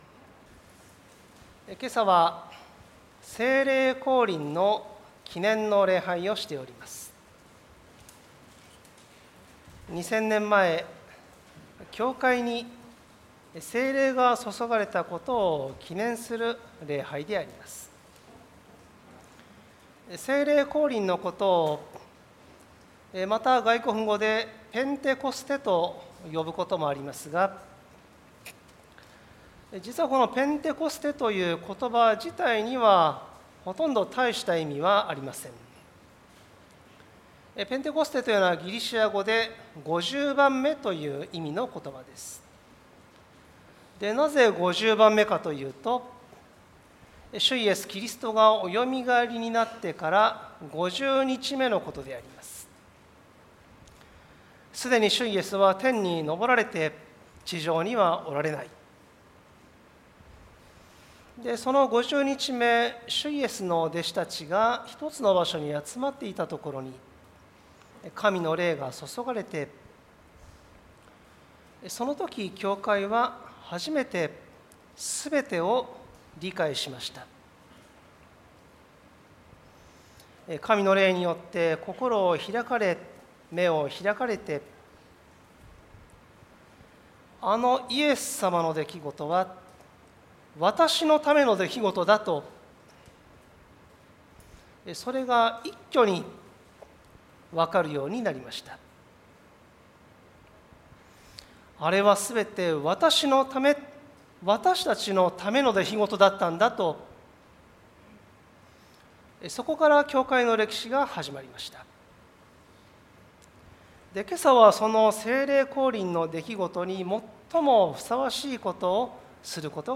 二千年前、教会に神の霊が注がれたことを記念する礼拝であります。
主日礼拝